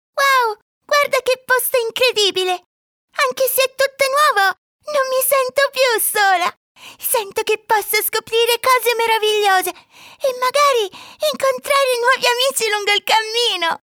Ana dil spikerleri